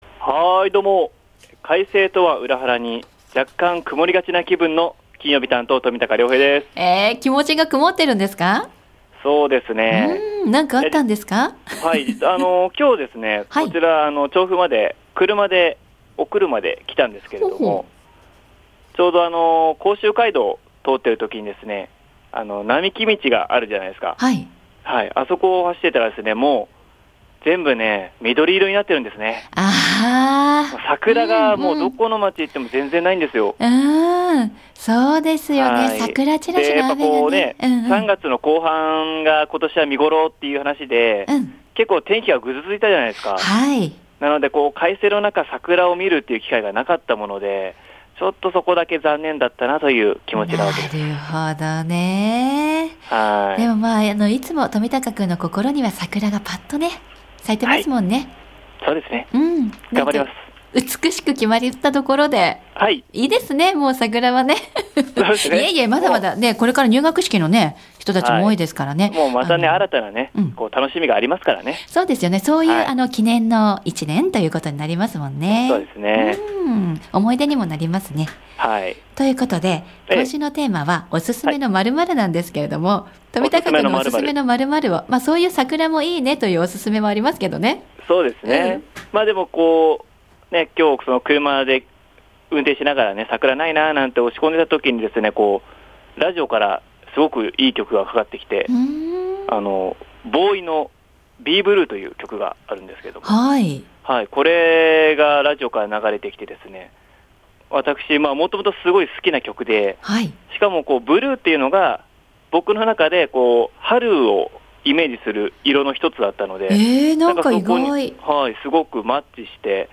午後のカフェテラス 街角レポート
本日は、つつじヶ丘にあります「Life Fit Club つつじヶ丘」さんにお伺いしました！